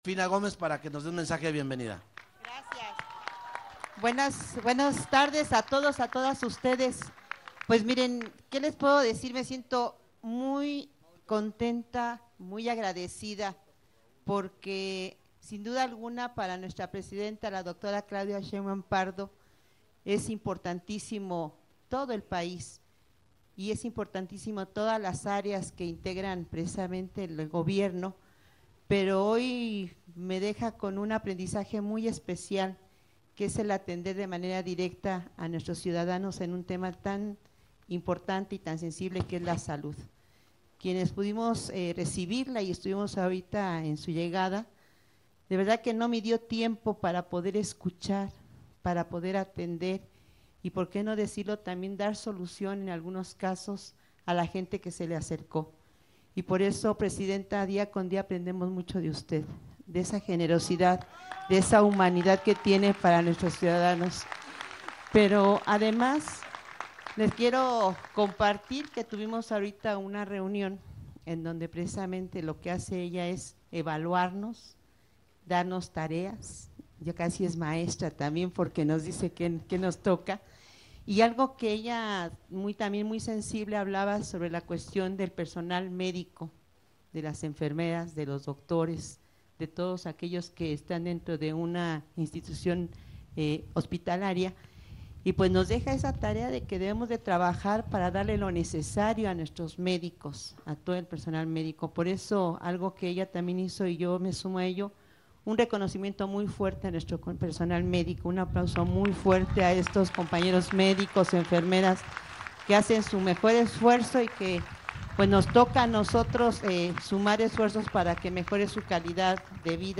AUDIO-MENSAJE-COMPLETO_DGA_SALUD-CASA-POR-CASA.mp3